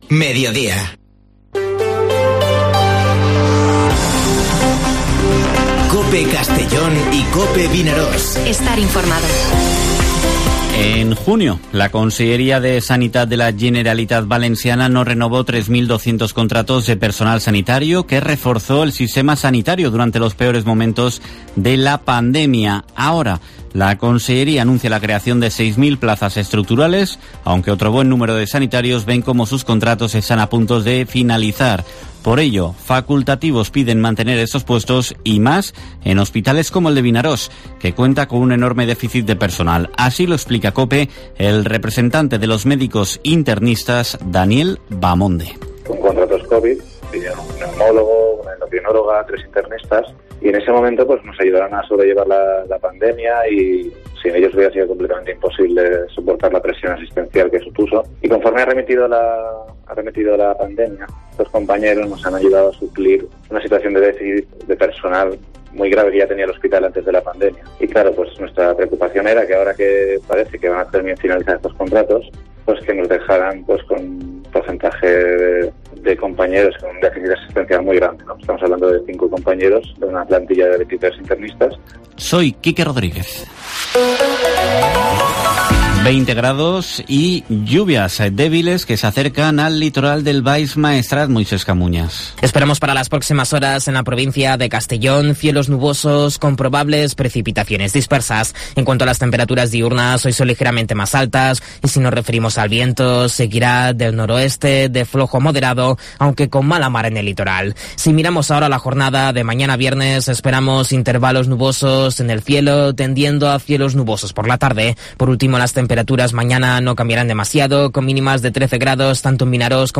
Informativo Mediodía COPE en la provincia de Castellón (11/11/2021)